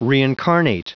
Prononciation du mot reincarnate en anglais (fichier audio)
Prononciation du mot : reincarnate